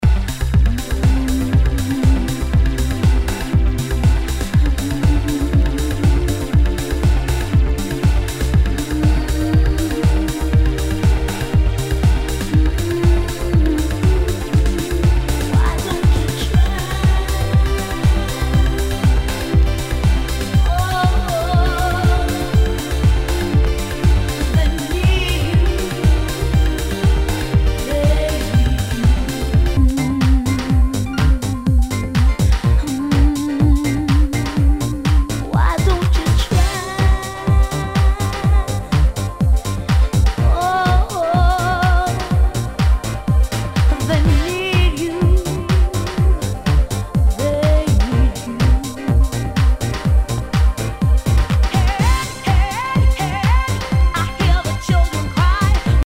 HOUSE/TECHNO/ELECTRO
ナイス！ヴォーカル・ハウス・クラシック！